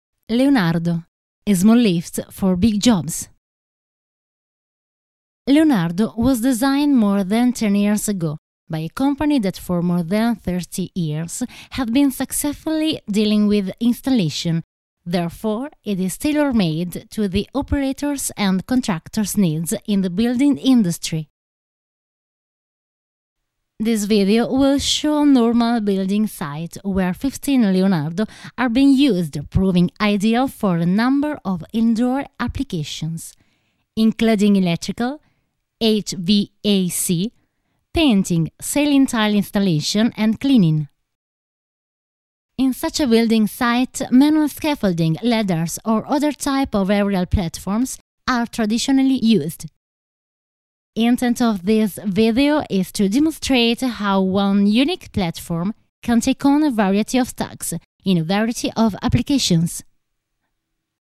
Sprecherin italienisch. 28 anni.
Sprechprobe: Industrie (Muttersprache):
Italian female voice over artist. 28 anni.